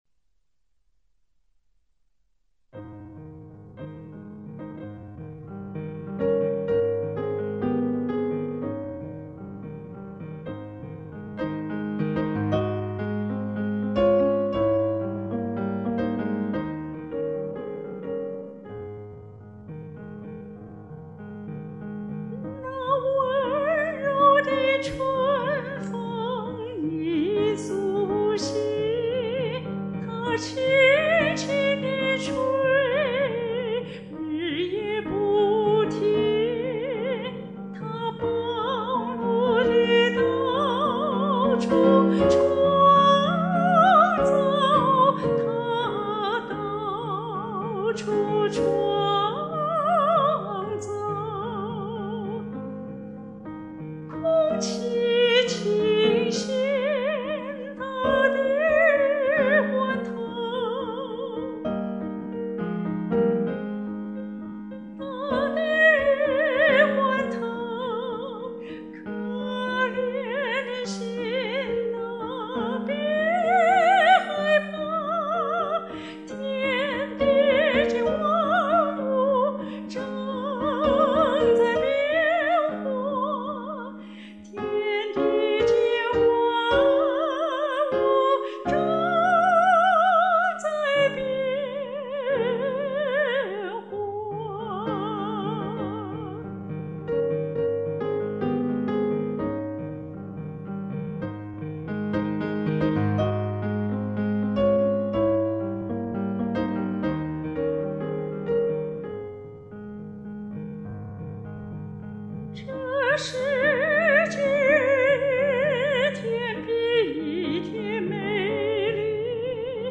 專業的演唱，情景動人！